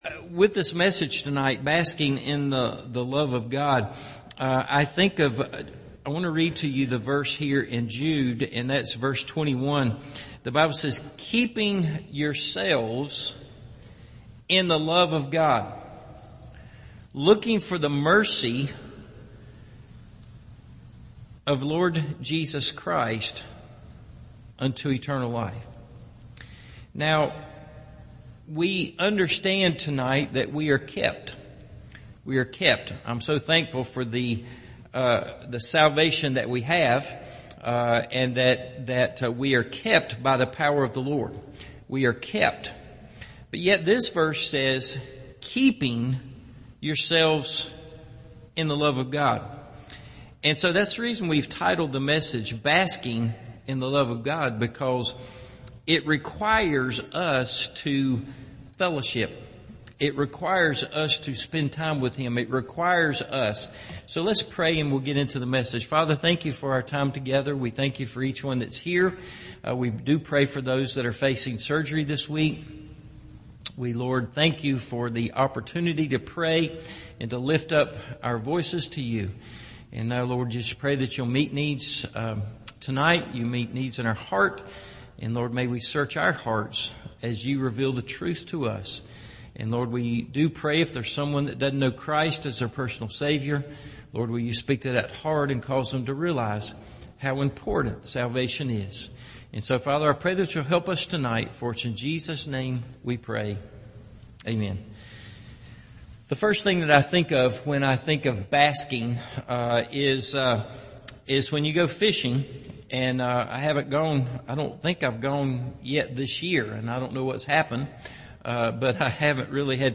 Basking In The Love Of God – Evening Service